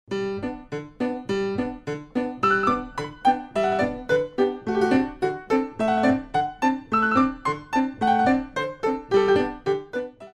Hand Clap with 2/4 Music